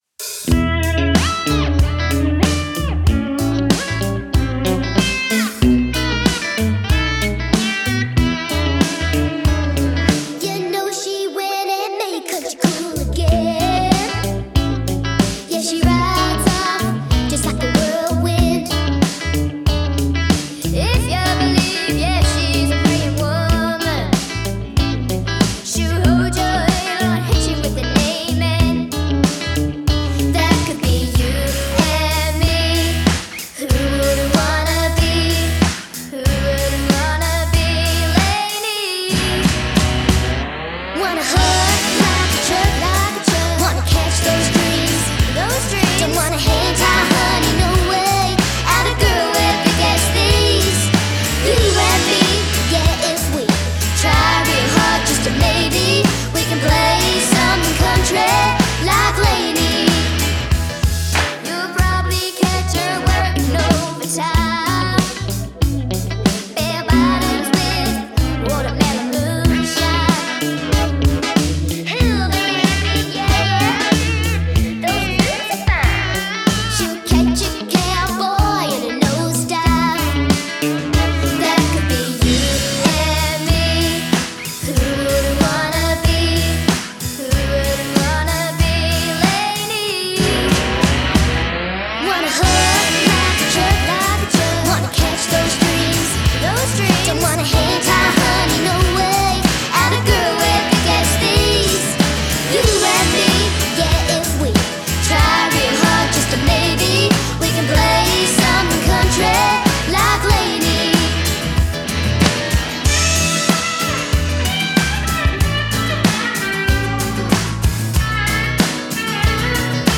A funky country rock song
recorded at Music South Studio